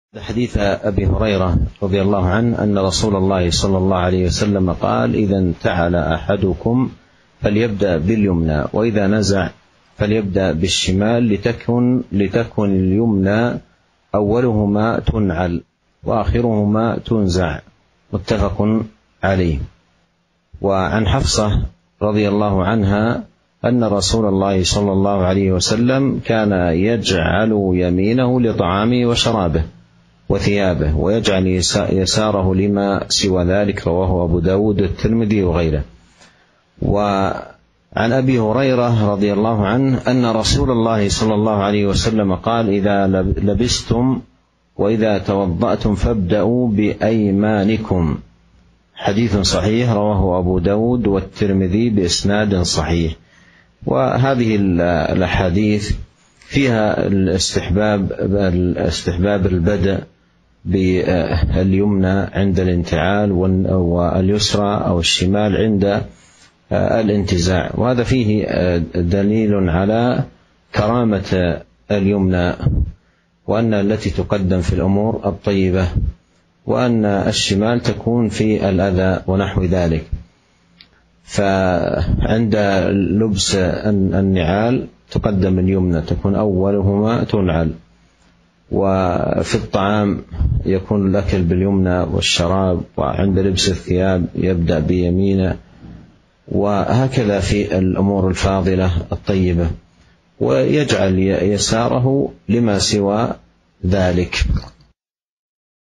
شرح حديث إذا انتعل أحدكم فليبدأ باليمنى وإذا نزع فليبدأ بالشمال